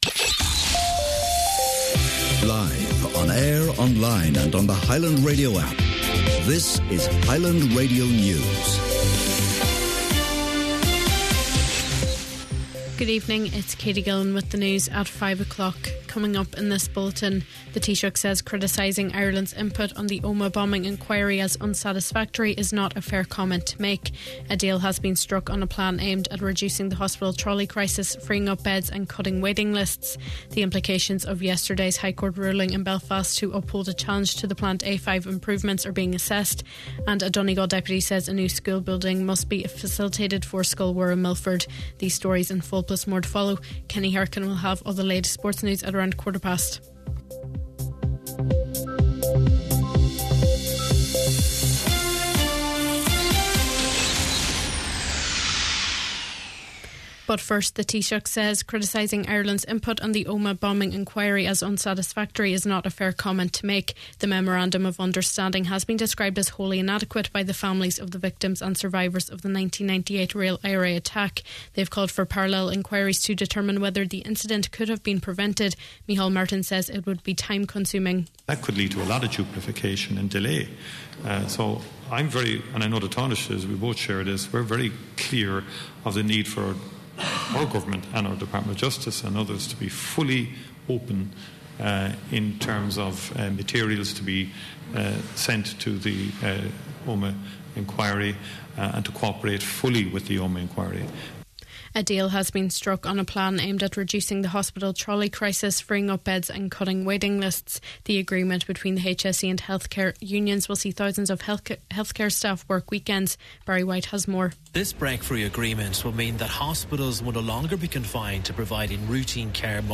Main Evening News, Sport and Obituary Notices – Tuesday June 24th